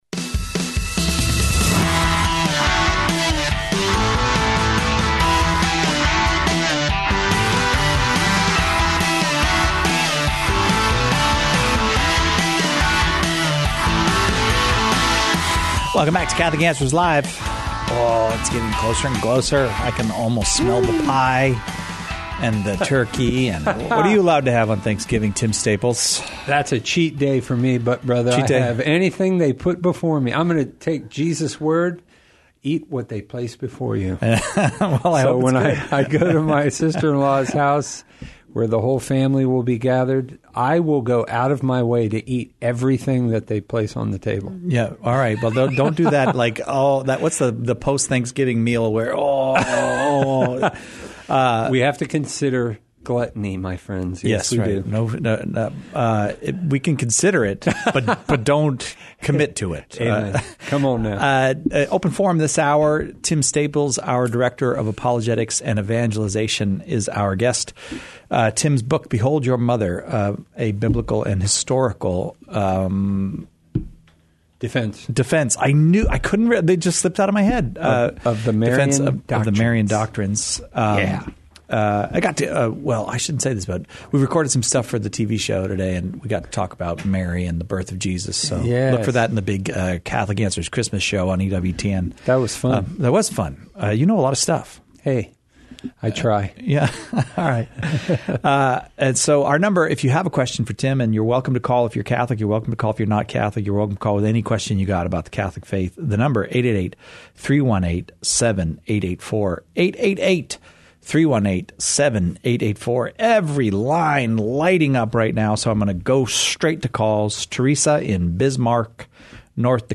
Our guests grapple with questions on every aspect of Catholic life and faith, the moral life, and even philosophical topics that touch on general religious belief.